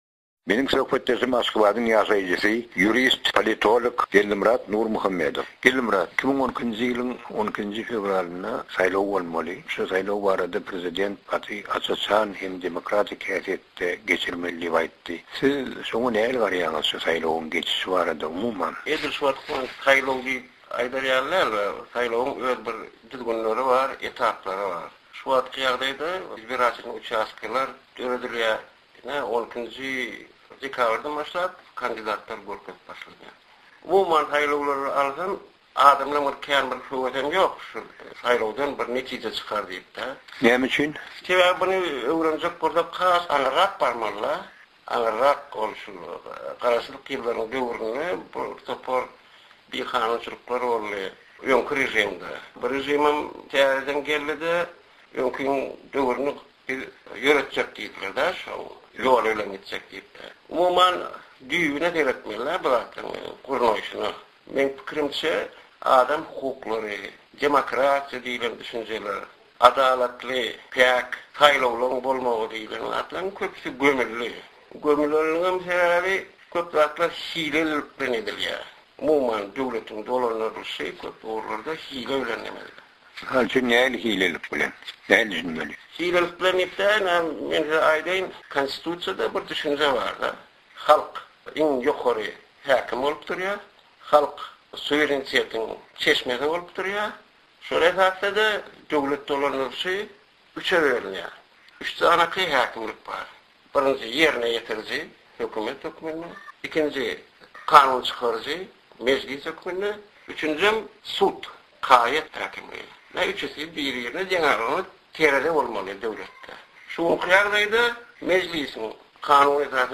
Geldimyrat Nurmuhammedowyň Azatlyk Radiosyna beren interwýusy, 8/12/11.